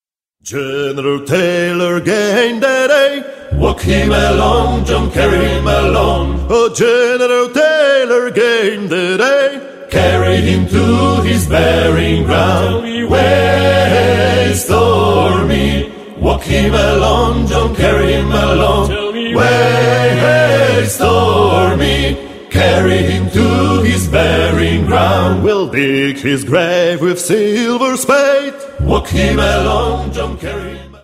(sł. i mel. trad.)